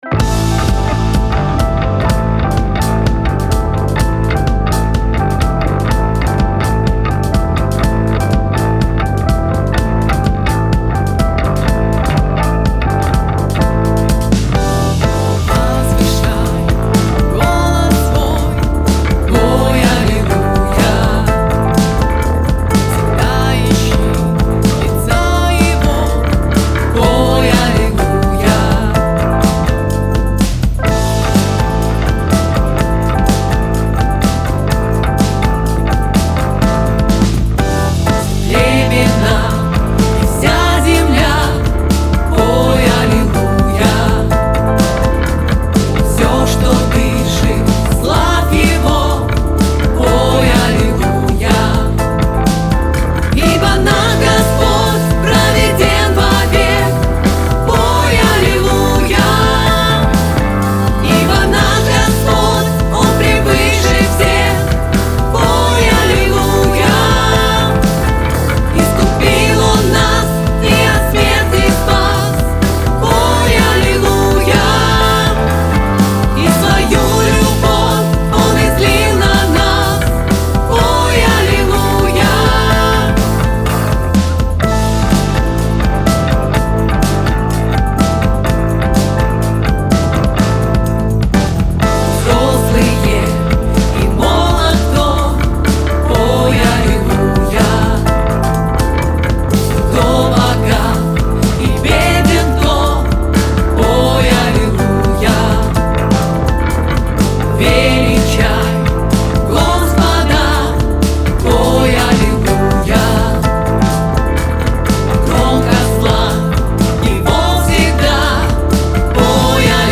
159 просмотров 309 прослушиваний 12 скачиваний BPM: 125